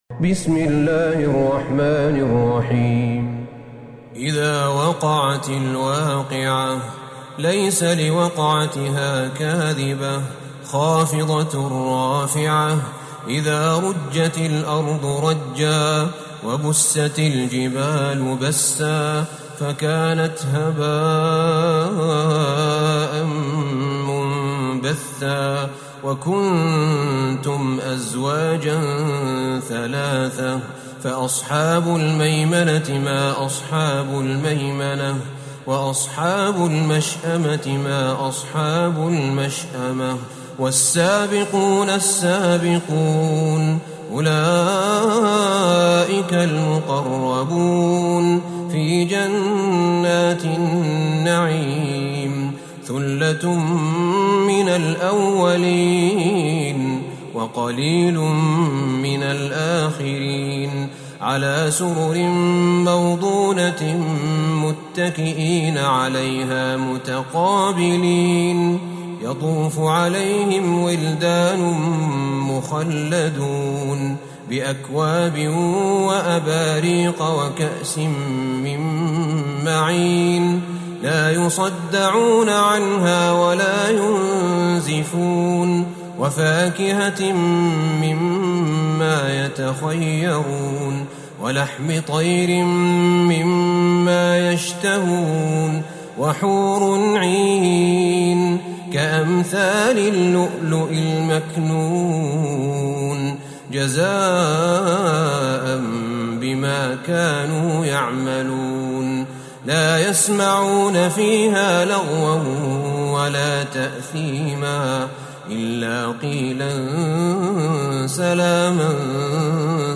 سورة الواقعة Surat Al-Waqi'ah > مصحف الشيخ أحمد بن طالب بن حميد من الحرم النبوي > المصحف - تلاوات الحرمين